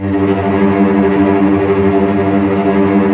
Index of /90_sSampleCDs/Roland - String Master Series/STR_Vcs Tremolo/STR_Vcs Trem f